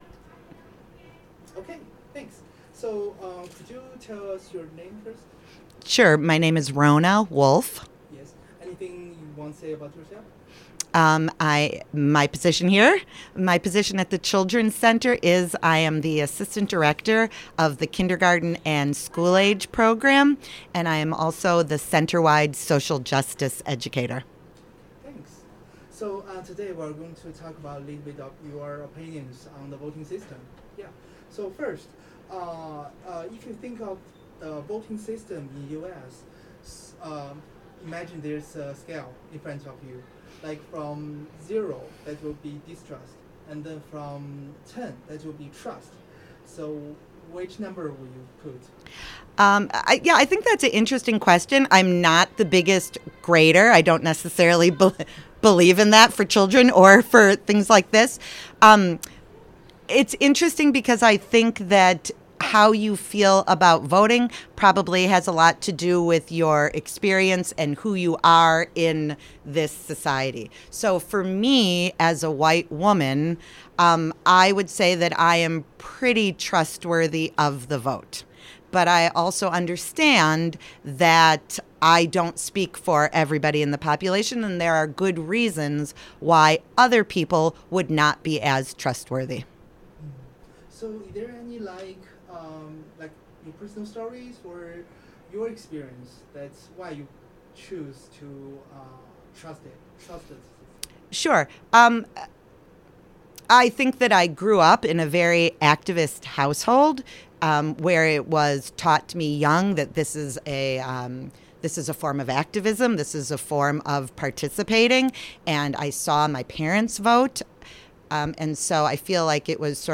Location UWM Children's Learning Center